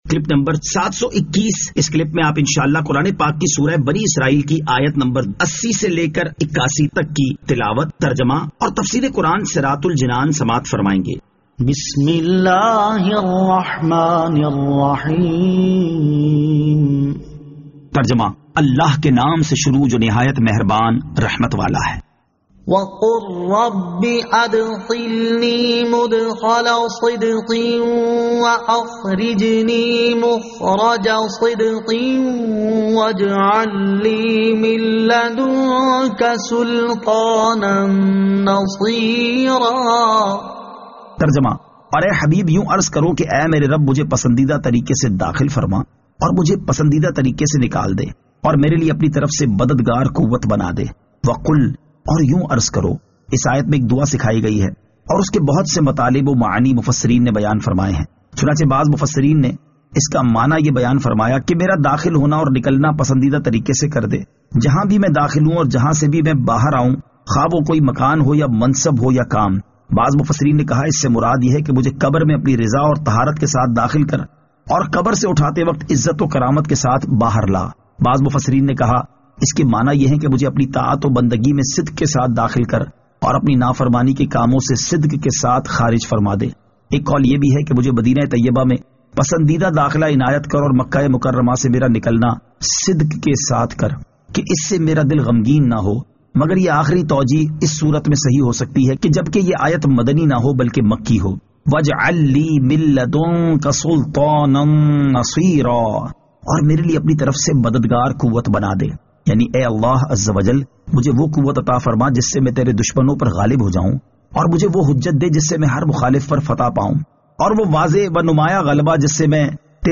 Surah Al-Isra Ayat 80 To 81 Tilawat , Tarjama , Tafseer